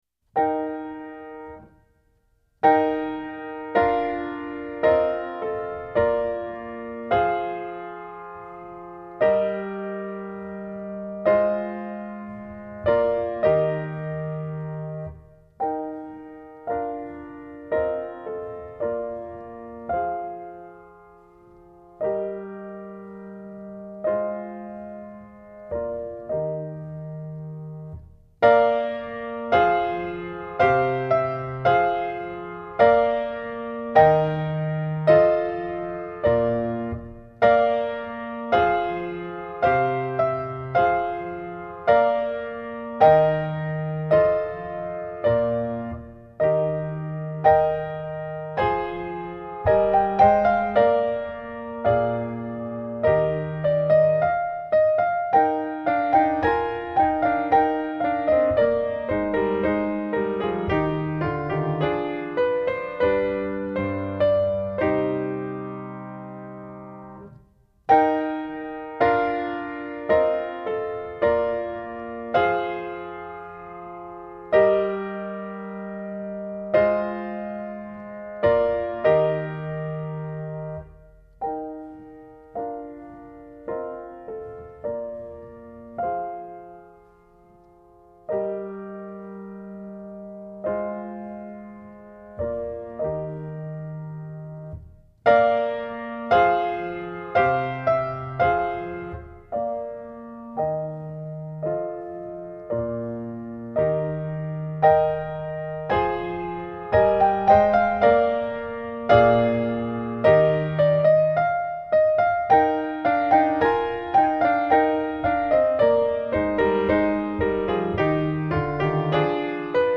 TROMBA SOLO • ACCOMPAGNAMENTO BASE MP3
Trombone
Base - Concerto